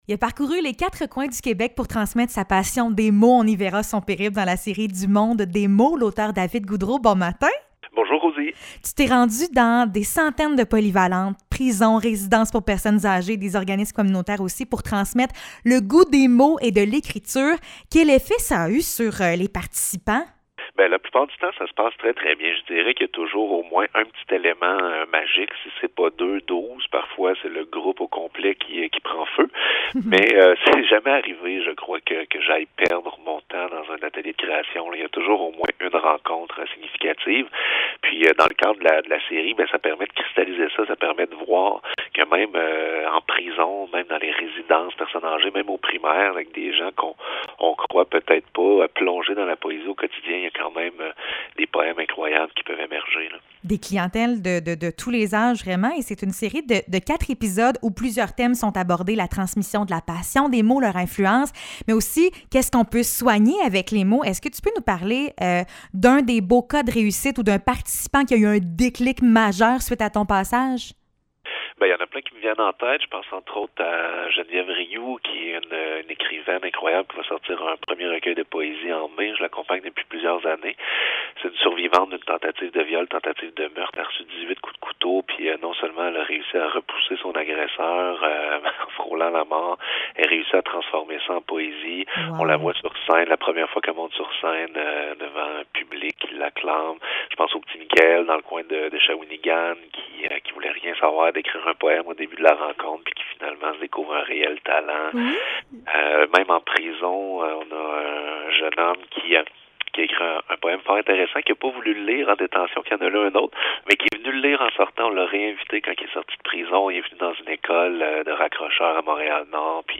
Entrevue avec l’auteur David Goudreault (6 mai 2022)